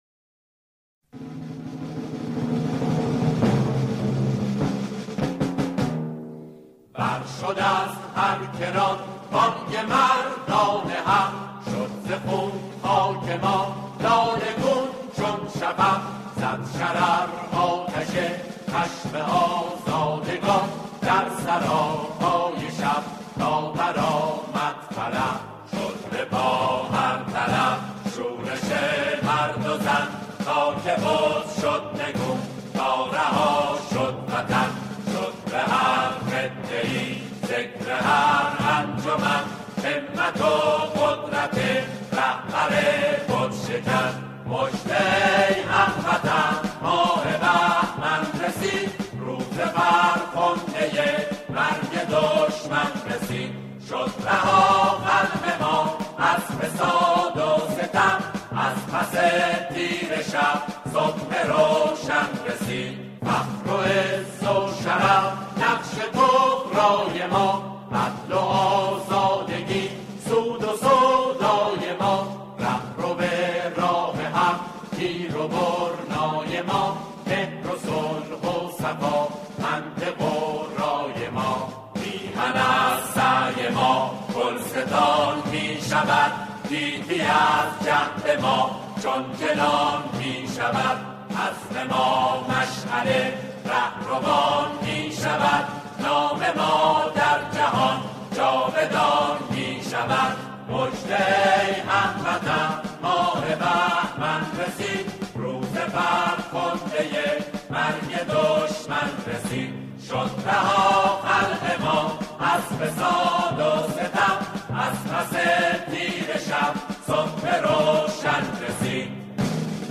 سرودهای دهه فجر